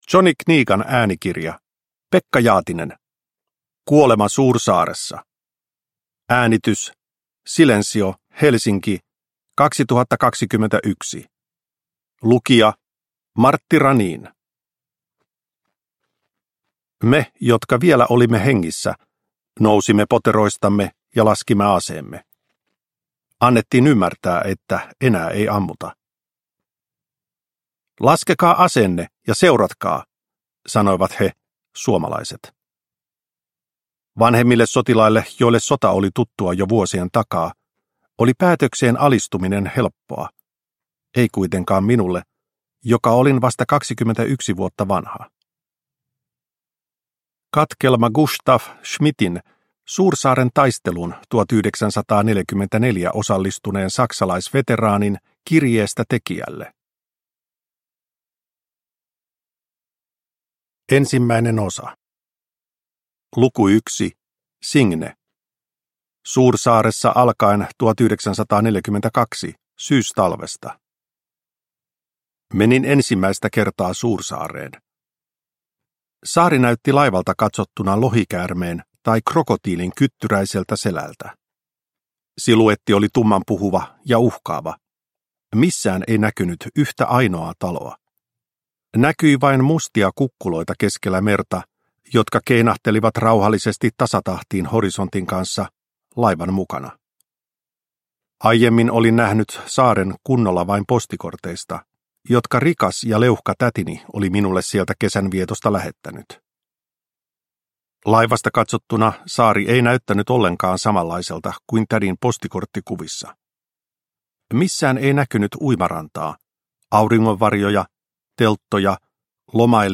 Kuolema Suursaaressa – Ljudbok